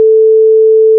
tremolo.wav